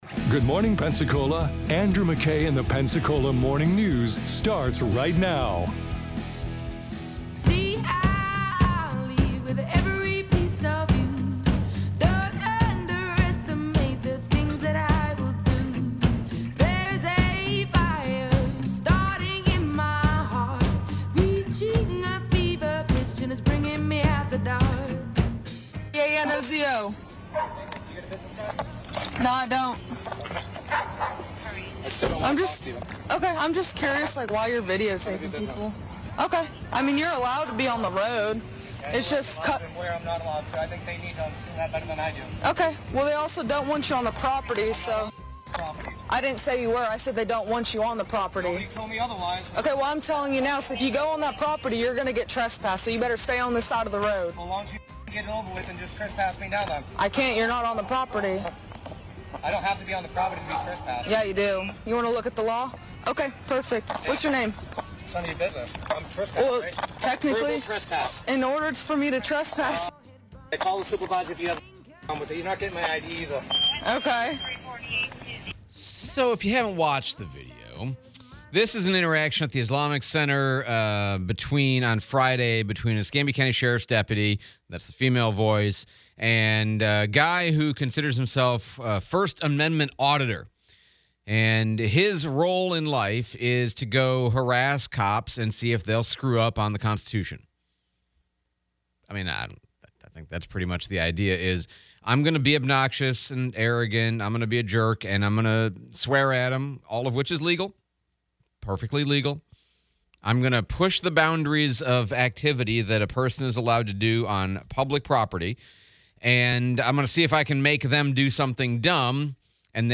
ECSO Body cam footage, Replay of Pensacola Mayor DC Reeves